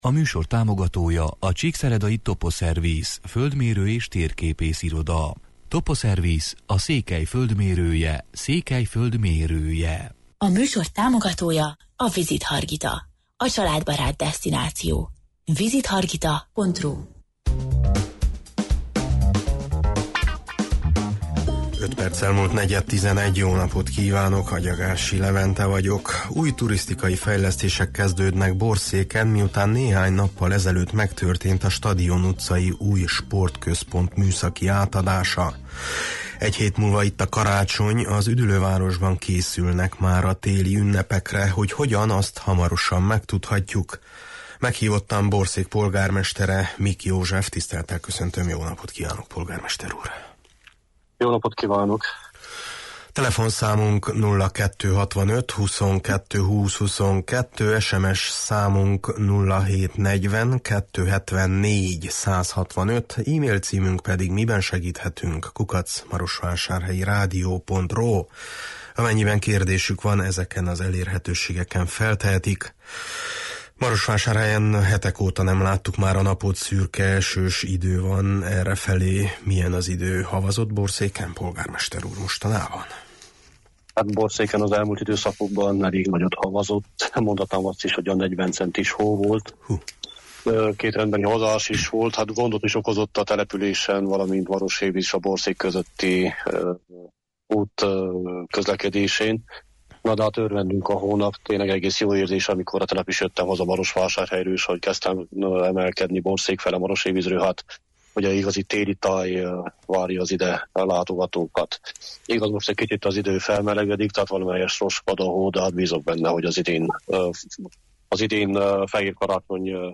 Meghívottam Borszék polgármesetere, Mik József: